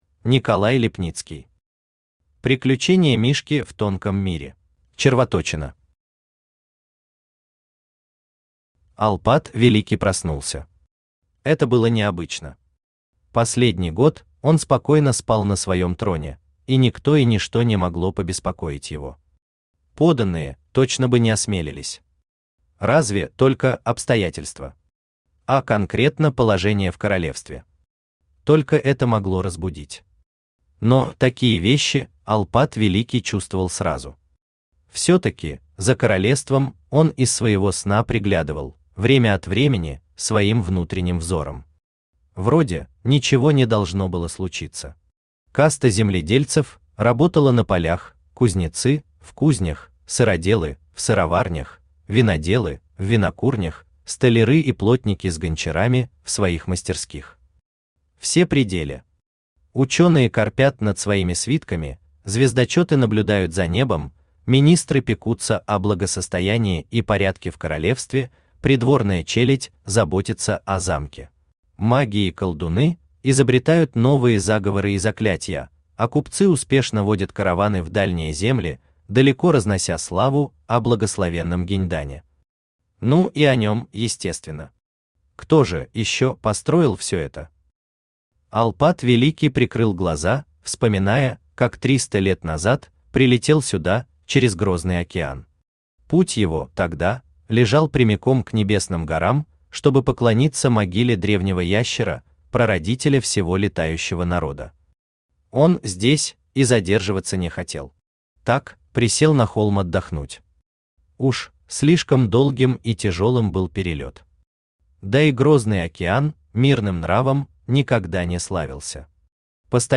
Аудиокнига Приключения Мишки в Тонком мире | Библиотека аудиокниг
Aудиокнига Приключения Мишки в Тонком мире Автор Николай Иванович Липницкий Читает аудиокнигу Авточтец ЛитРес.